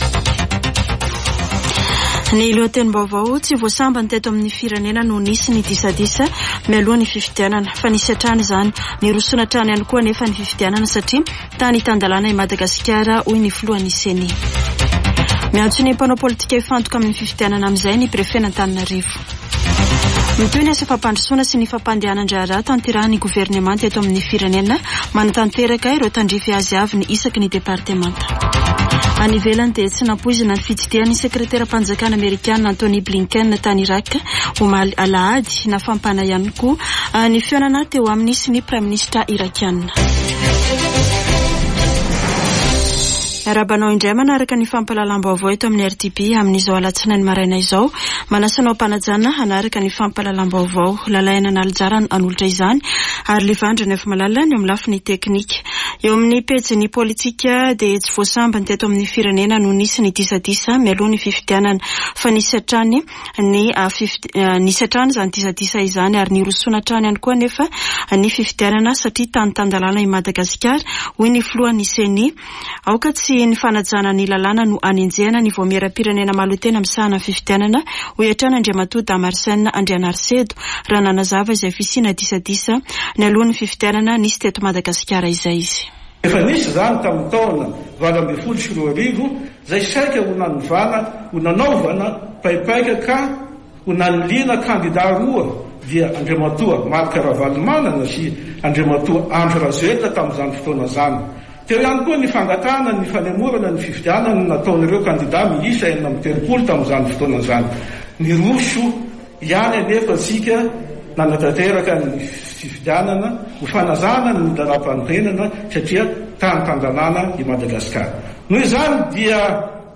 [Vaovao maraina] Alatsinainy 6 nôvambra 2023